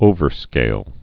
(ōvər-skāl) or o·ver·scaled (-skāld)